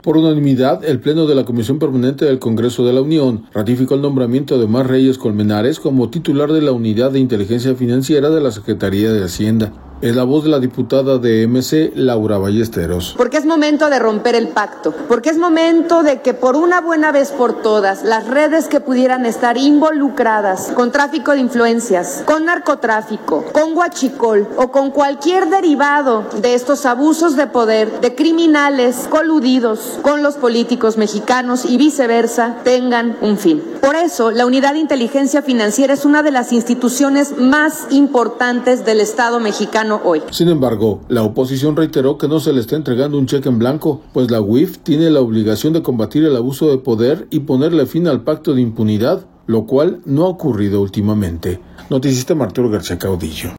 audio Por unanimidad, el Pleno de la Comisión Permanente del Congreso de la Unión, ratificó el nombramiento de Omar Reyes Colmenares como titular de la Unidad de Inteligencia Financiera de la Secretaría de Hacienda. Es la voz de la diputada de MC, Laura Ballesteros.